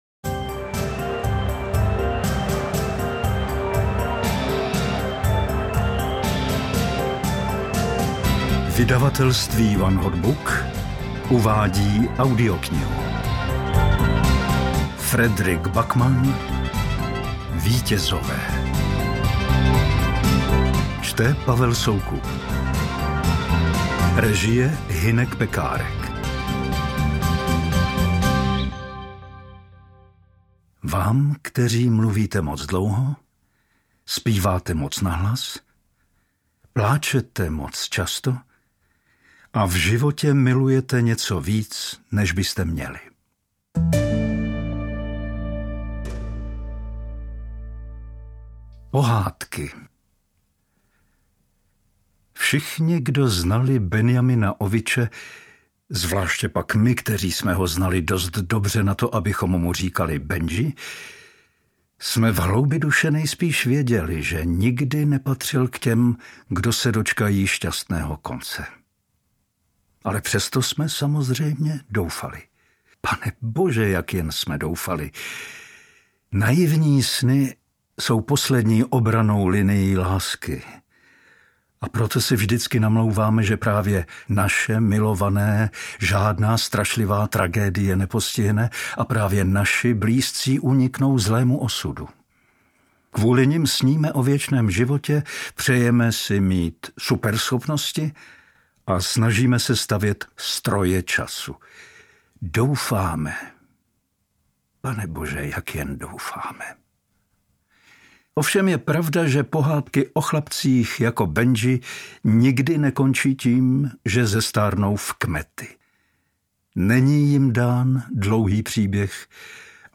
Interpret:  Pavel Soukup
AudioKniha ke stažení, 110 x mp3, délka 25 hod. 47 min., velikost 1379,0 MB, česky